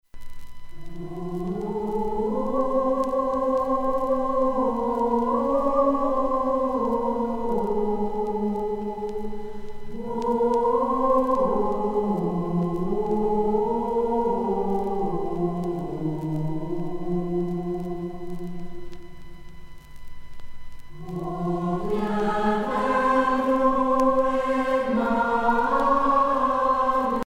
mélodie médiévale
Pièce musicale éditée